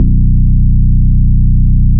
ELECTRO BASS.wav